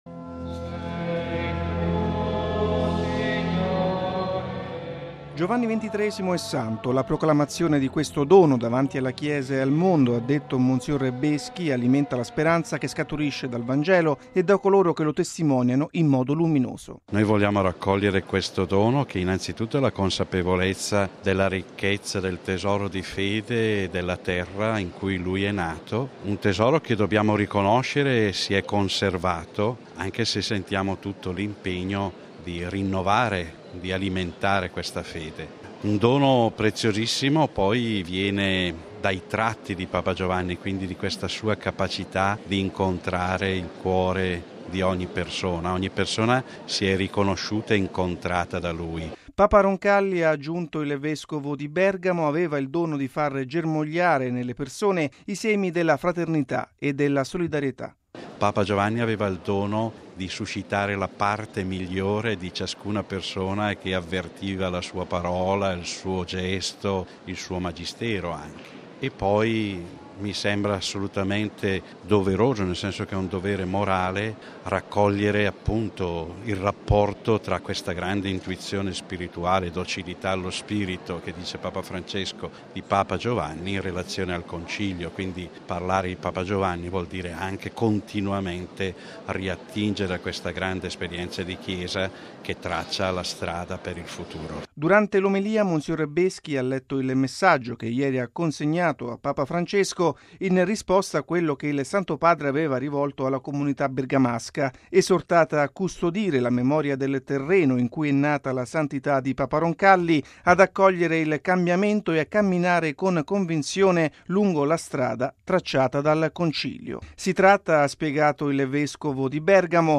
◊   Molti fedeli bergamaschi hanno partecipato stamani nella chiesa romana di San Carlo al Corso, dove Papa Roncalli era stato ordinato vescovo, alla celebrazione eucaristica di ringraziamento per la Canonizzazione di Giovanni XXIII.